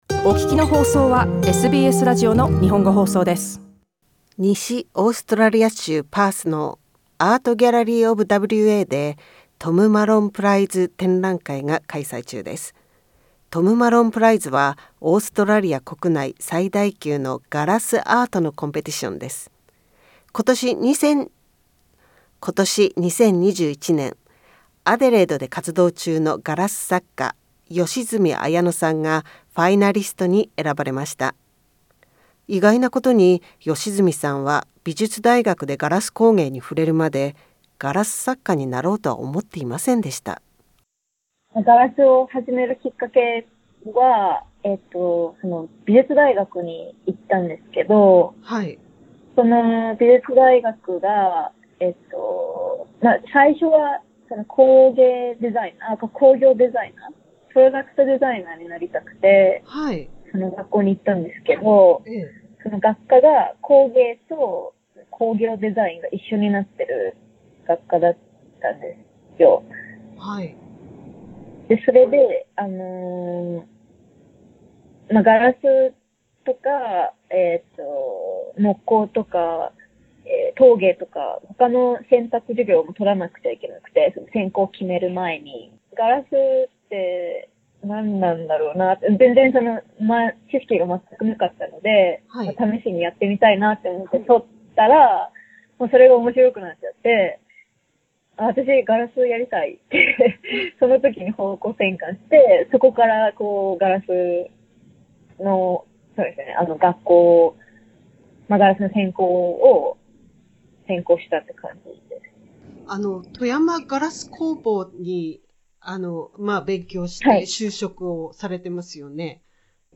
インタビューでは、ガラス作家になるまでや日豪でのガラス作家の交流の違いなどを聞きました。